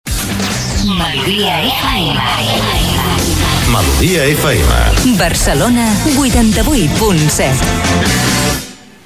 Identificació de l'emissora a Barcelona i freqüència.